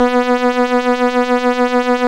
Index of /90_sSampleCDs/Roland LCDP09 Keys of the 60s and 70s 1/KEY_Stylophone/KEY_Stylophone